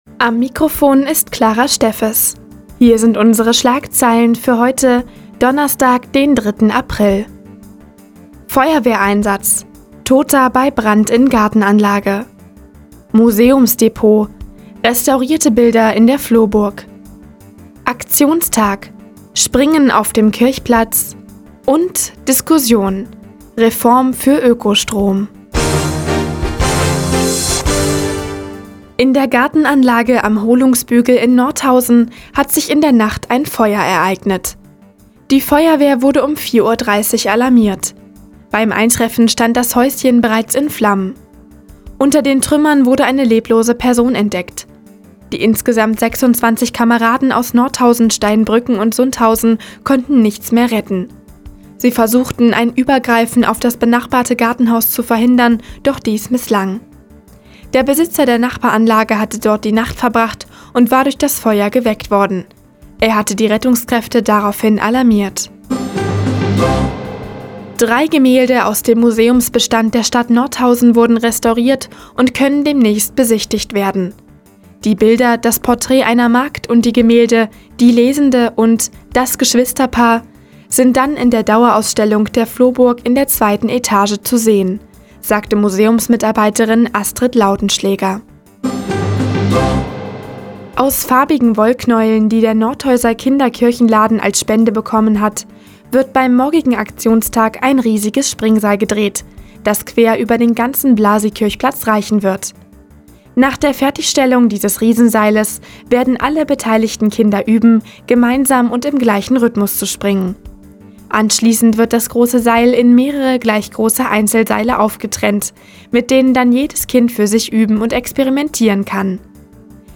Do, 15:00 Uhr 03.04.2014 „Der Tag auf die Ohren“ OKN (Foto: OKN) Seit Jahren kooperieren die Nordthüringer Online-Zeitungen und der Offene Kanal Nordhausen. Die tägliche Nachrichtensendung des OKN ist jetzt hier zu hören.